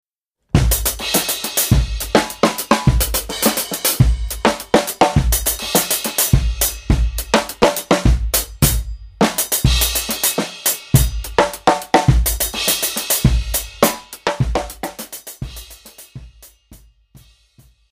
Multi-Percussion